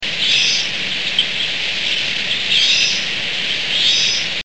Warbling Vireo (Vireo gilvus)
b. A soft, wheezy,
querulous twee (P,L).